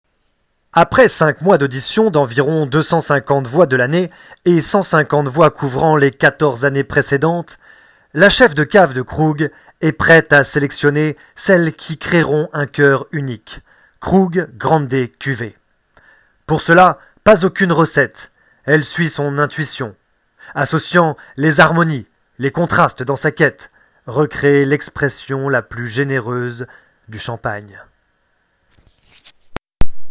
voix champagne 2
30 - 40 ans - Baryton